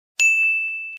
Ding Sound Effect.mp3